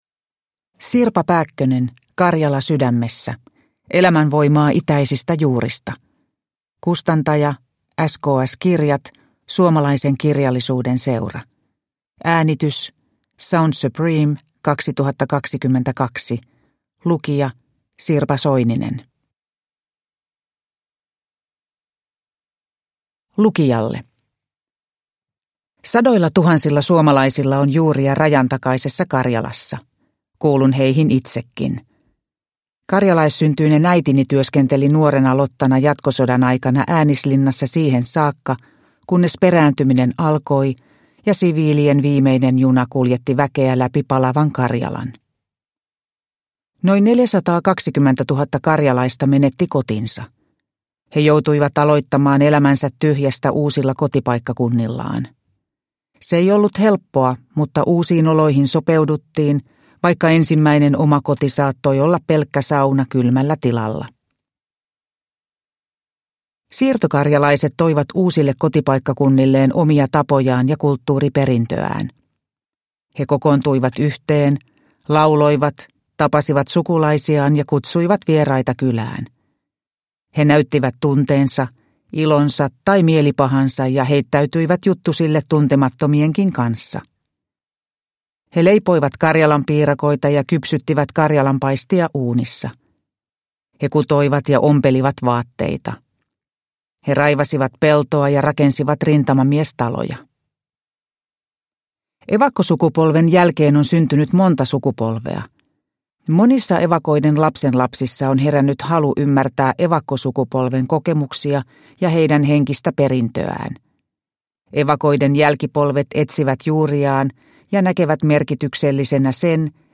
Karjala sydämessä – Ljudbok – Laddas ner